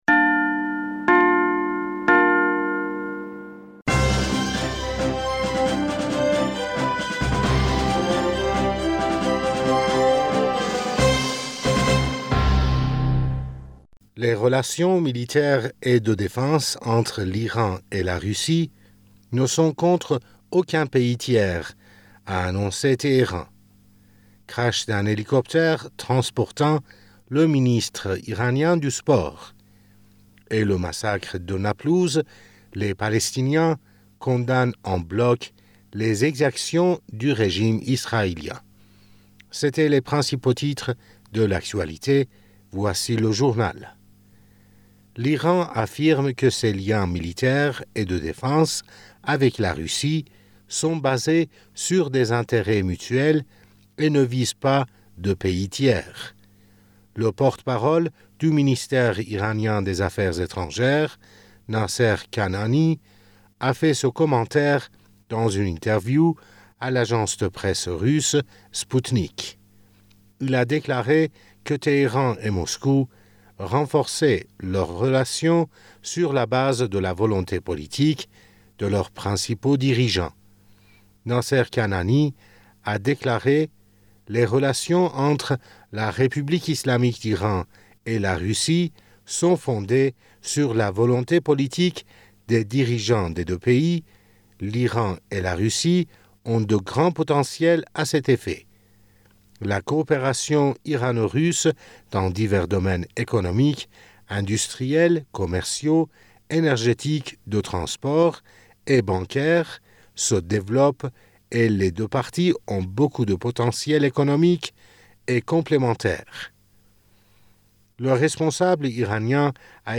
Bulletin d'information du 24 Février